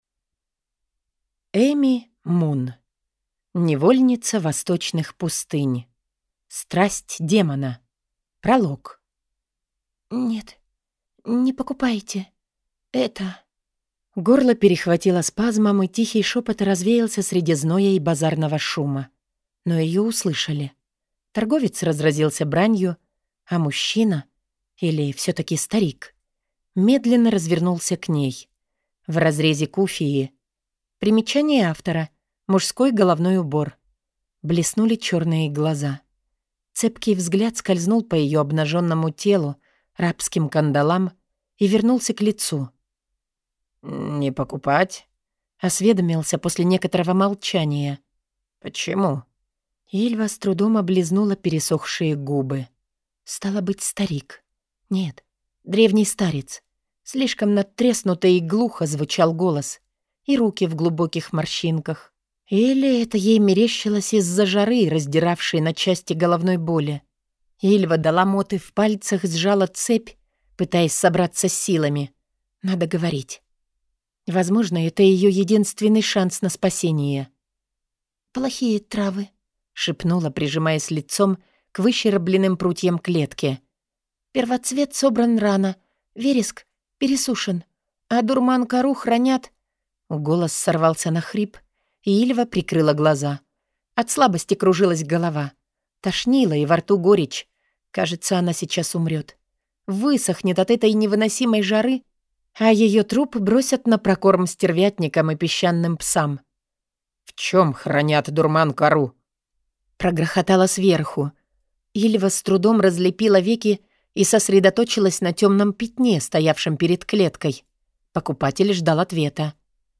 Аудиокнига Невольница Восточных пустынь. Страсть демона | Библиотека аудиокниг